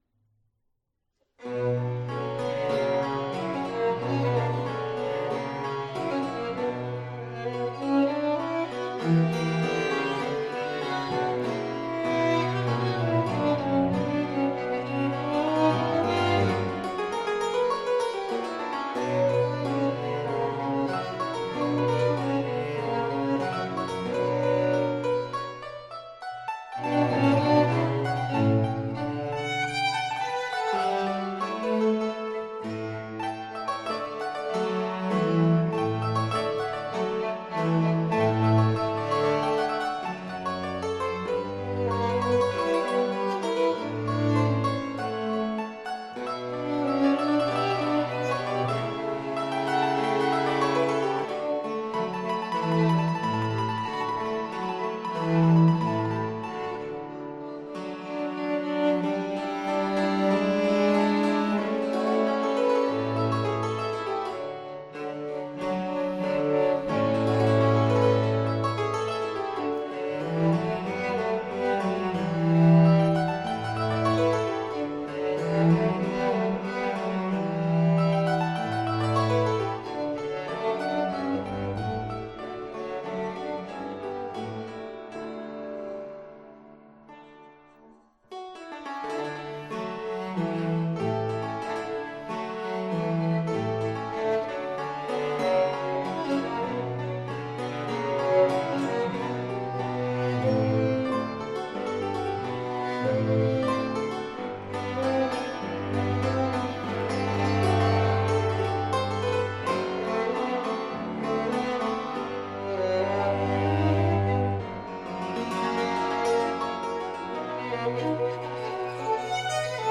TitleTrio no. 2 in C Minor, op. 6, I. Andante non molto, 1765: from Sonates en trio pour le clavecin avec accompagnement de violon et basse ad libitum
PerformerThe Raritan Players
Subject (lcsh) Trio sonatas